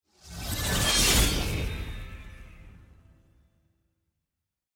sfx-ranked-ui-lp-aov.ogg